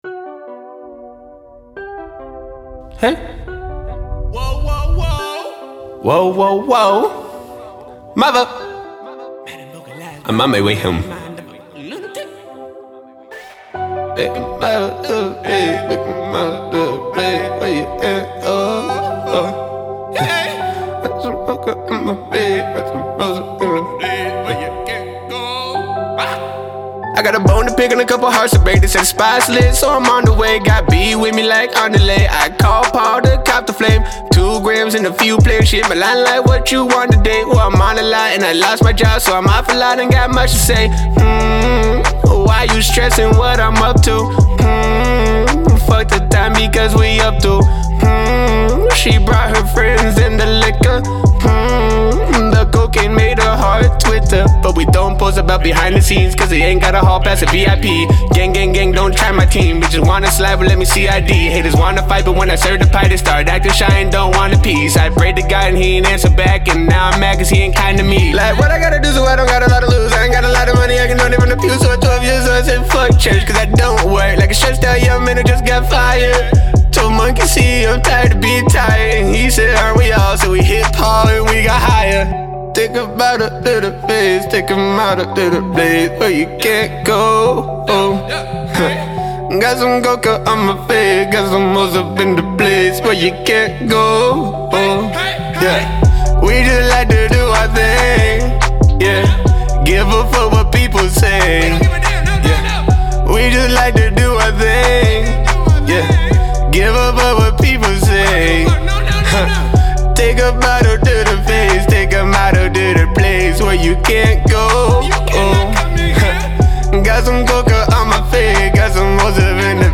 Seattle Hip Hop.